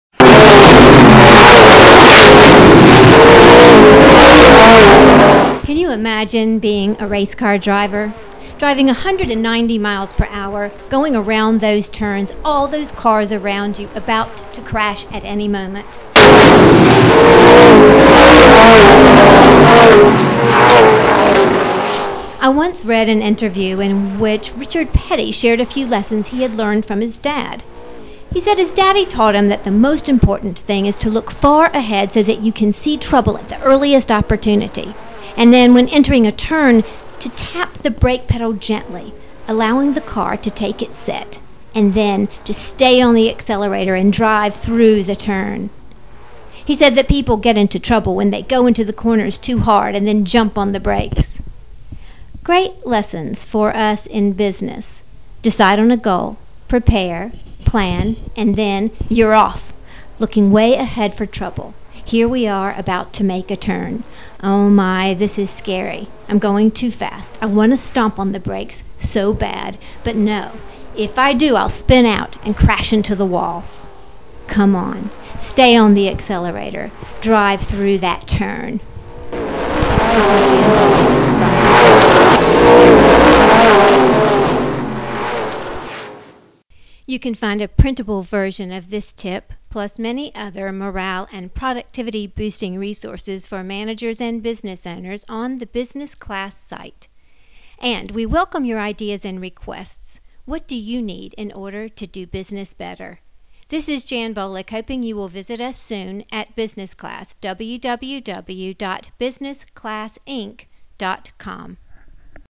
Audio version:  WARNING it starts out very LOUD